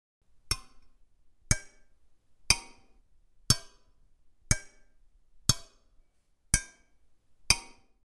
Pickaxe Mining Clank OWI
bang clang clank ding hit impact metal metallic sound effect free sound royalty free Sound Effects